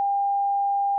Sonido con poca intensidad.
Escucha el sonido con poca intensidad.